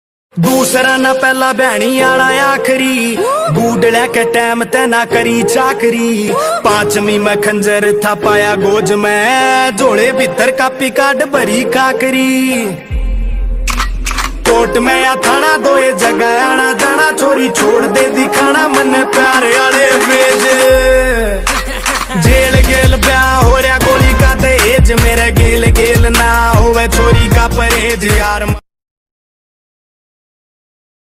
only music tune